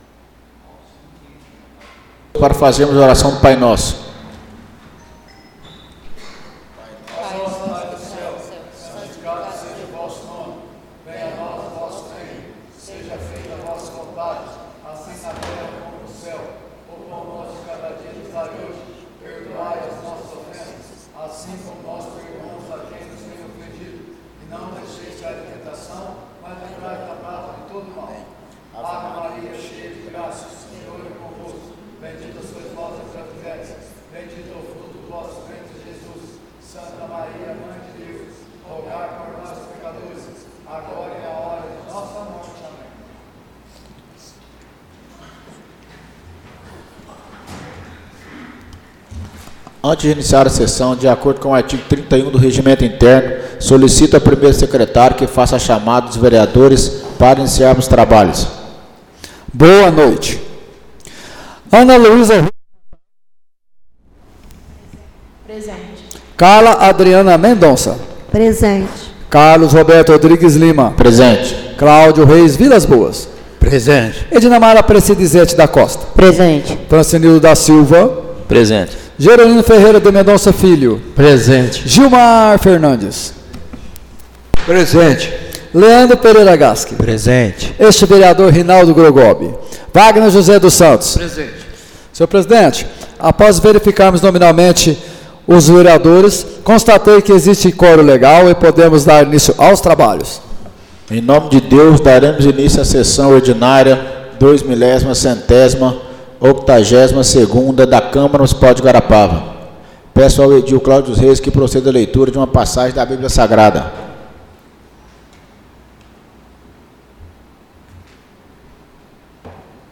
Áudio da Sessão Ordinária de 09/09/2024